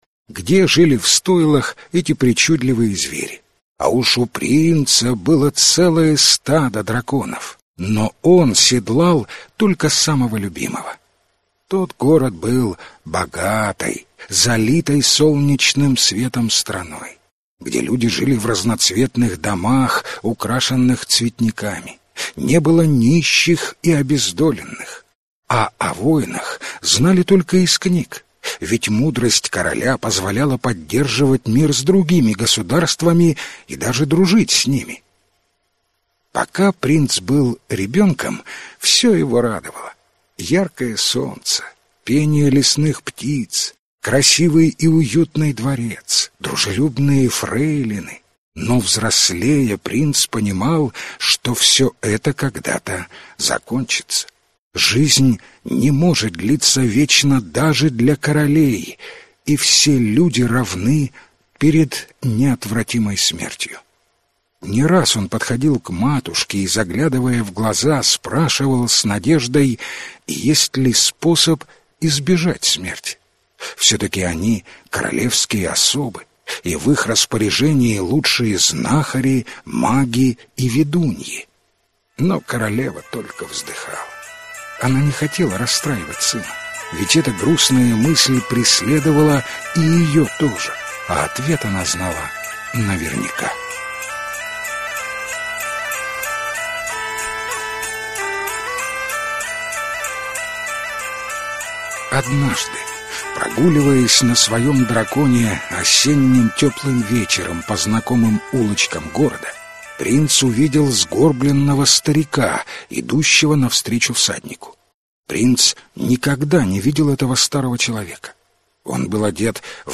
Аудиокнига Сердце дракона (сказки о драконах) | Библиотека аудиокниг